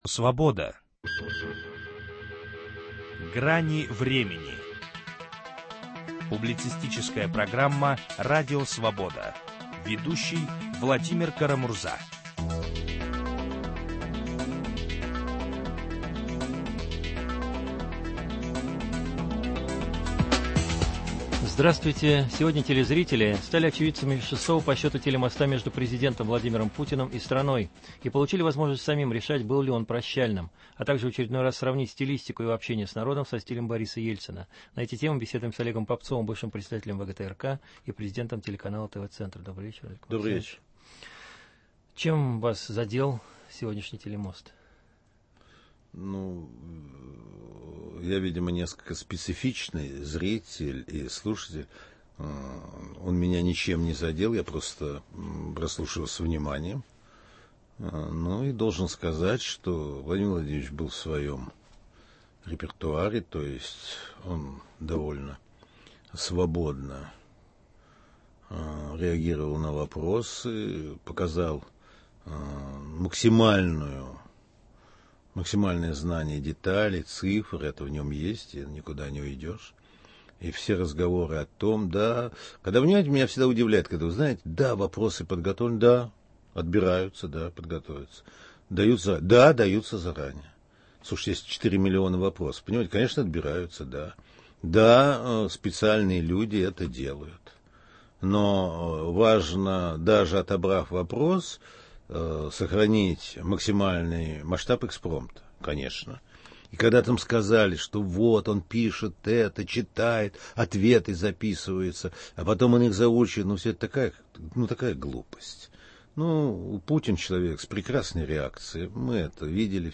Что нового заметили профессионалы в стилистике очередного президентского телемоста? На эту тему беседуем с Олегом Попцовым, бывшим председателем ВГТРК и президентом ТВЦ.